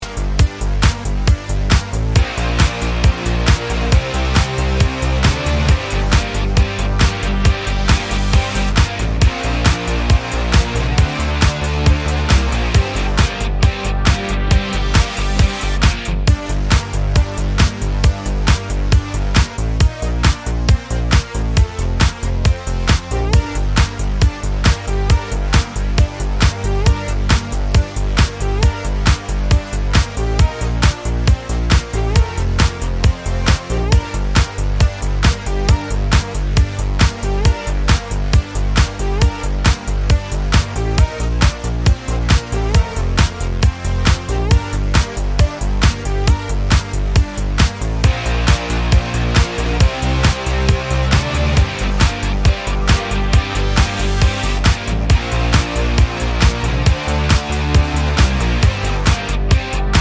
No Crowd SFX or Backing Vocals Pop (2000s) 3:36 Buy £1.50